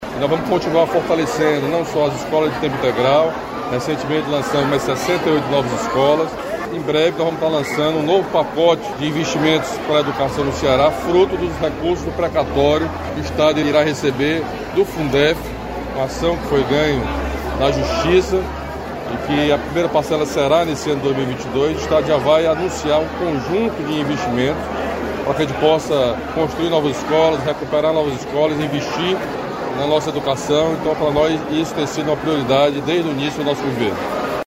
Nova sede da Escola de Ensino Médio Deputado Manoel Rodrigues é inaugurada em Fortaleza
Na ocasião, o governador Camilo Santana anunciou ainda mais investimentos em educação pelo Governo do Ceará.